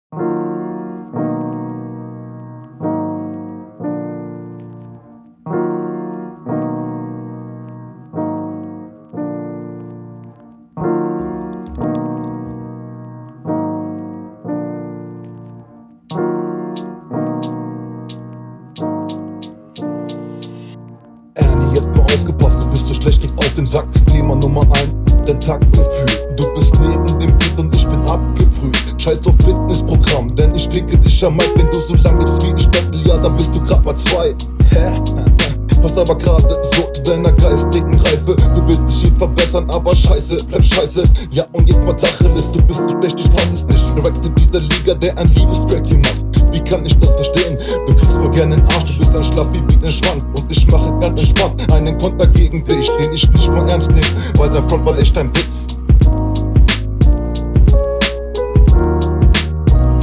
Mische find ich nicht gut, in der HR1 hast Du Dich besser angehört.